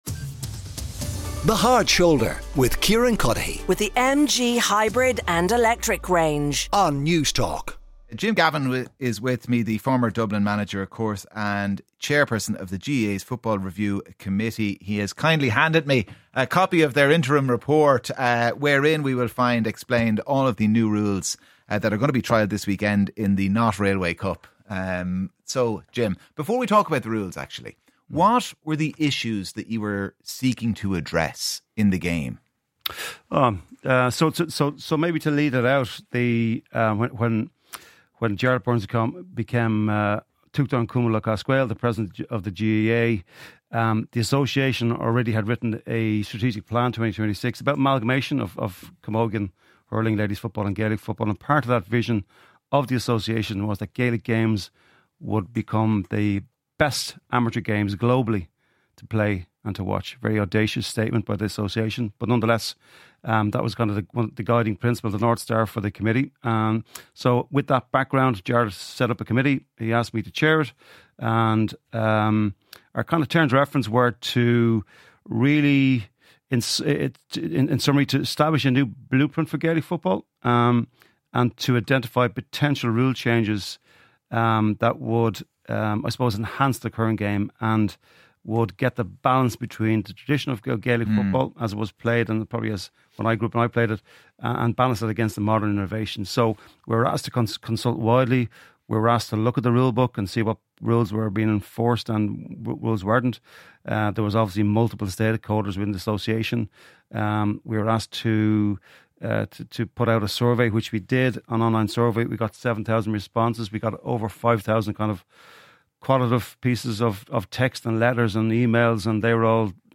For this week’s Thursday Interview, Kieran Cuddihy is joined by legendary GAA manager and Chairperson of GAA Football Review, Jim Gavin!